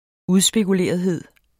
Udtale [ ˈuðsbeguˌleˀʌðˌheðˀ ]